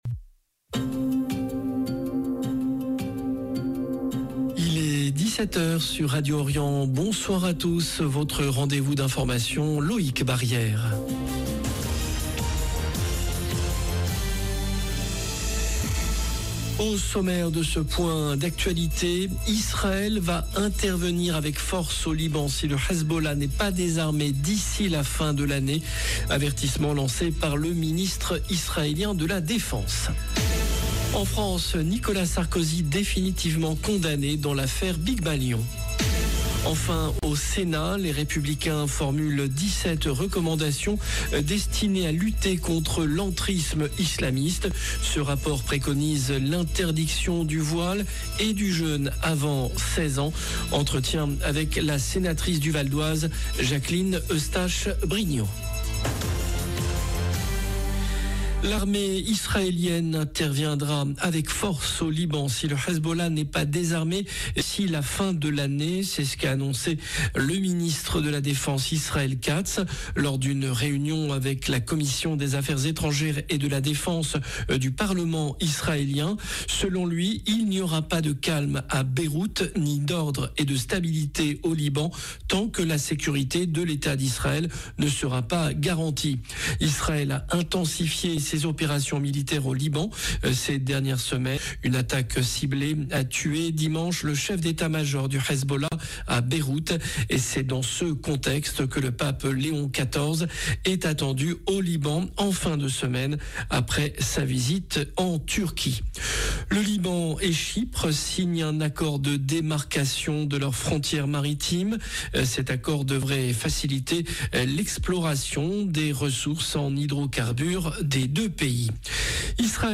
En France, Nicolas Sarkozy définitivement condamné dans l’affaire Bygmalion Enfin, au Sénat, les Républicains formulent 17 recommandations destinées à lutter contre l’entrisme islamiste. Ce rapport préconise l’interdiction du voile et du jeûne avant 16 ans. Entretien avec la sénatrice du Val-d’Oise Jacqueline Eustache Brinio. 0:00 8 min 24 sec